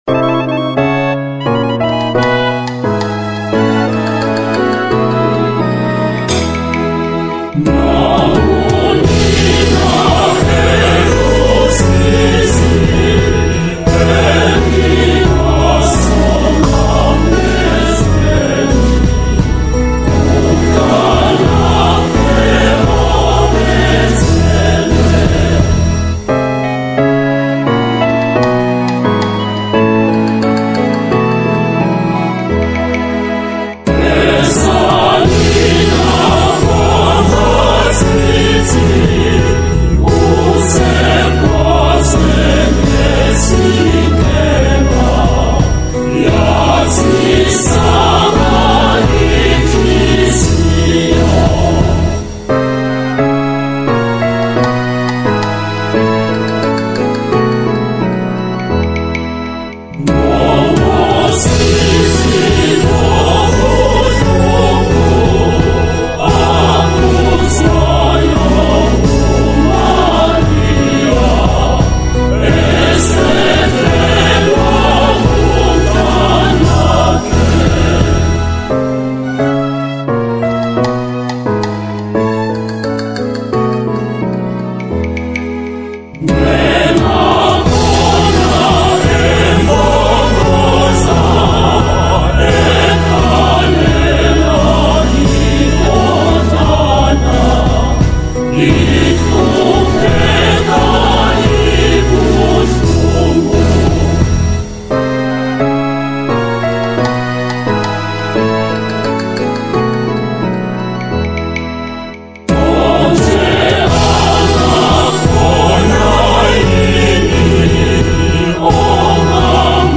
A Soulful Zulu Hymn